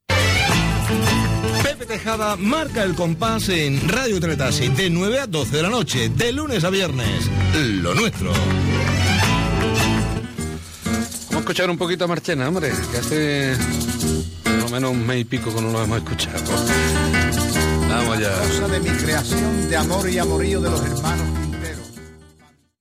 Indicatiu del programa (veu Justo Molinero) i presentació d'un tema musical
Musical